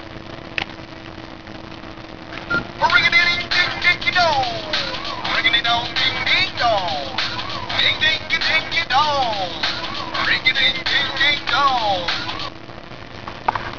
Wave file (.WAV extension) of this ringtone on my handset